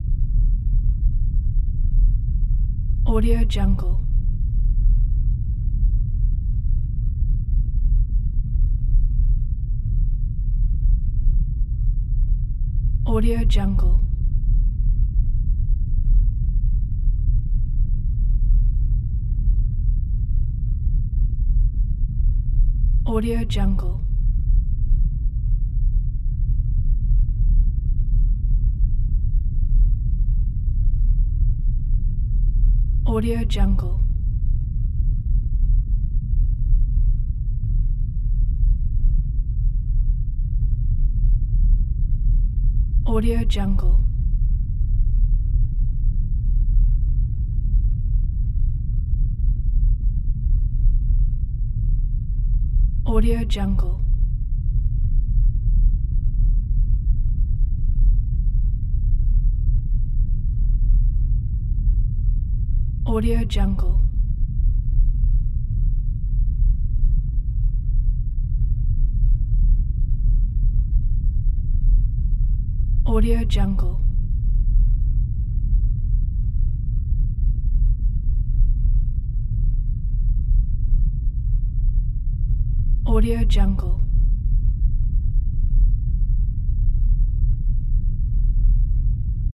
دانلود افکت صدای غرش زلزله
Sample rate 16-Bit Stereo, 44.1 kHz
Looped No